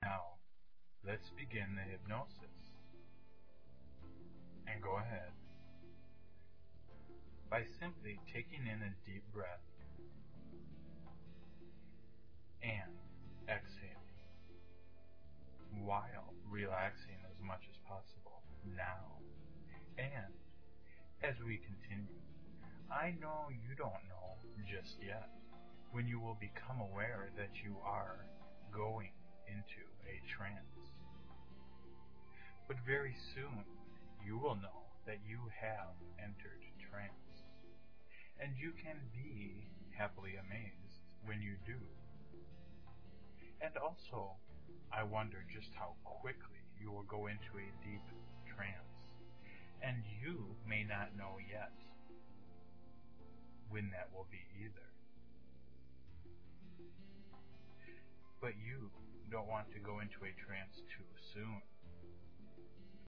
Digitally mastered sound.
Inducing music and trance deepening sounds intertwined with hypnosis.
A complete and full hypnosis session.
32kbps-stop-smoking-hypnosis-sample.mp3